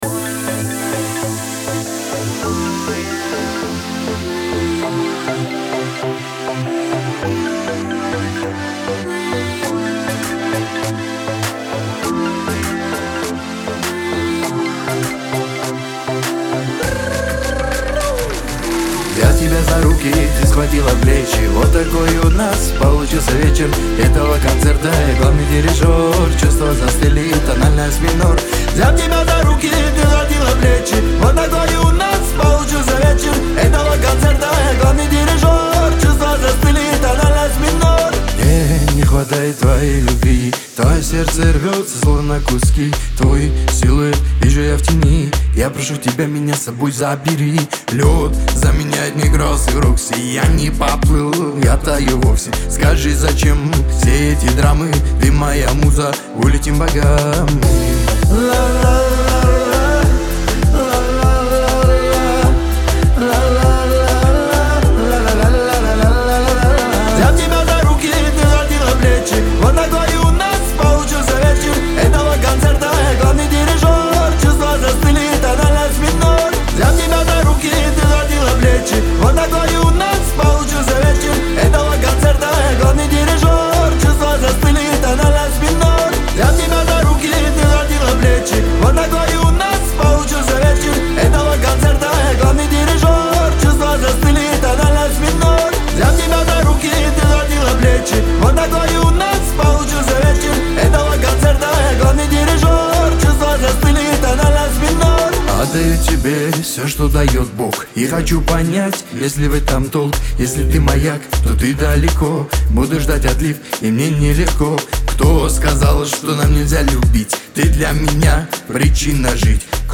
Кавказ – поп
Лирика